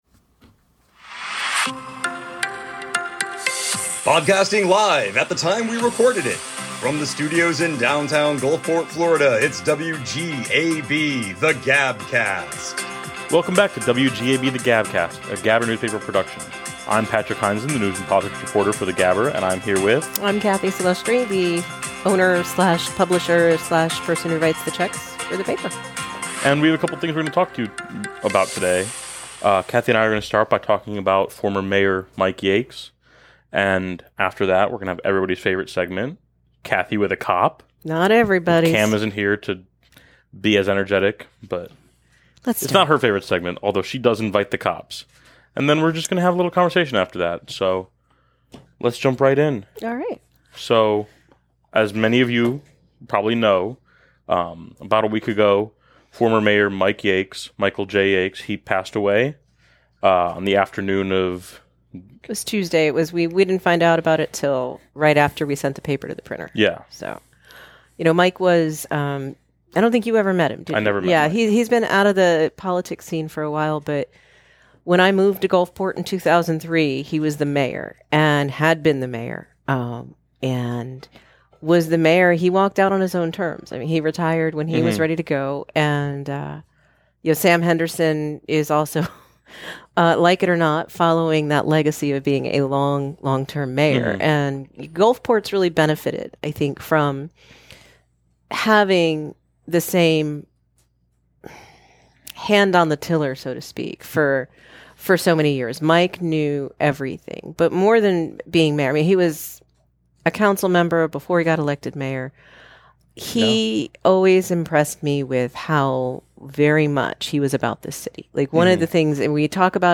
Covering weekly news in Gulfport Florida and South Pinellas. Produced live (when we taped it) in downtown Gulfport, Florida.